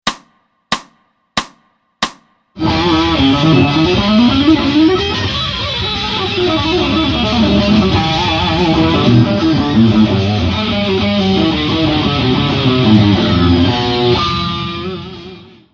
LEGATO SHRED LICK
For fun, for this installment of Psycho Licks, I'm adding another lick from this era—a pretty speedy legato passage!
With the exception of the bent notes (raising G to G#), every single pitch in this puppy is pulled straight outta the E Mixolydian scale (E-F#-G#-A-B-C#-D).
(*You can hear the lick FAST by clicking
INFGROOVESLegato(FAST).mp3